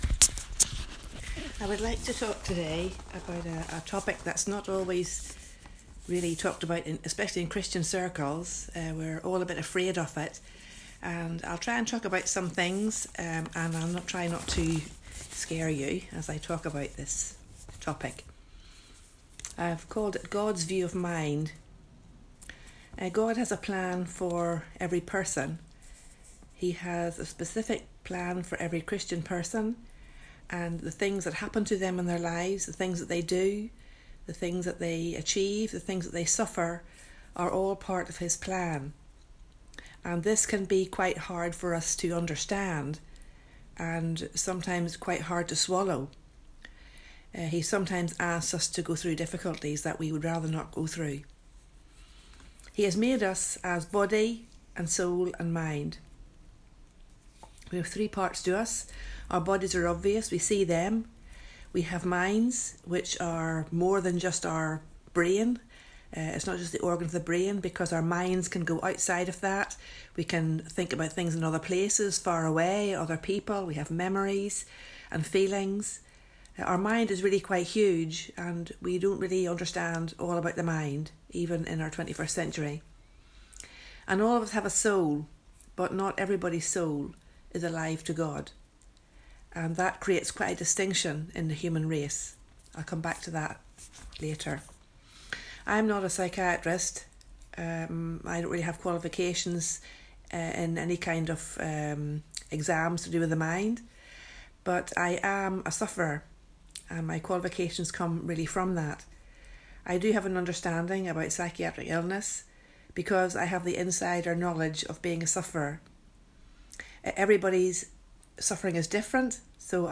Introductory talk on this topic….